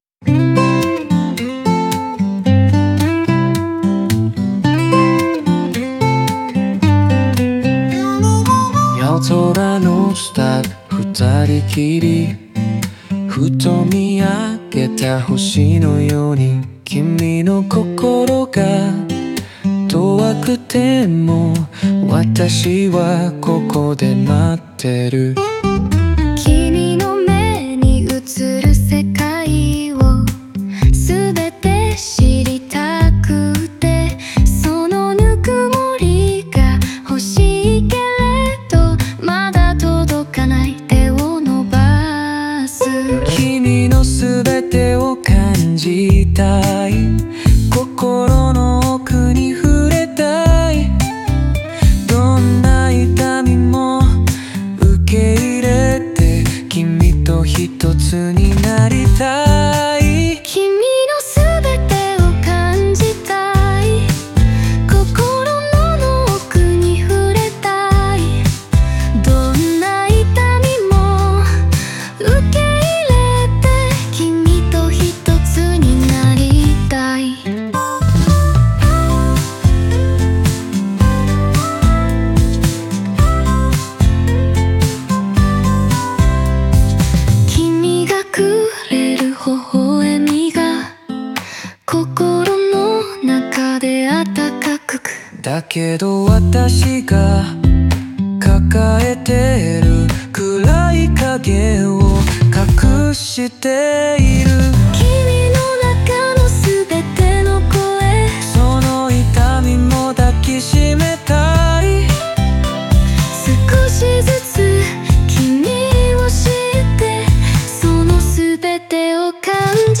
オリジナル曲♪
デュエット形式で、二人の心の葛藤や想いが交互に響き合い、深い絆を感じさせる内容です。